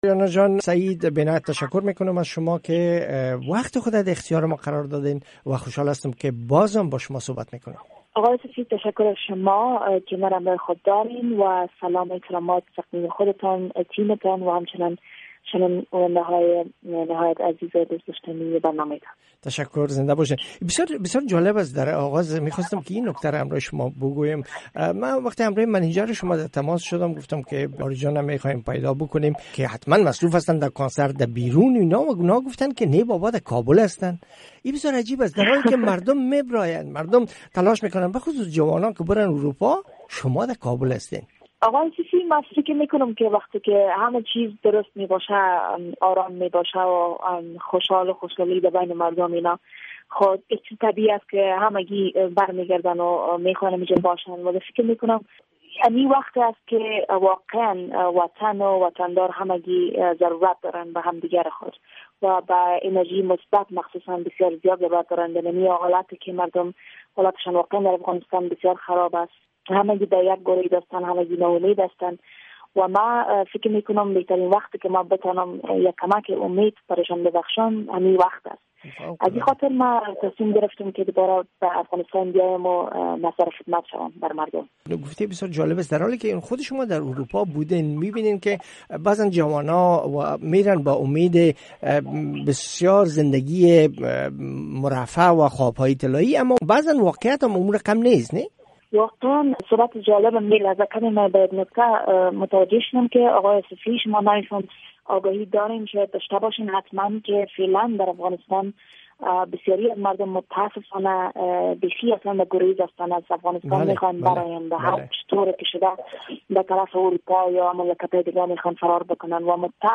شرح کامل مصاحبه با آریانا سعید را از اینجا بشنوید: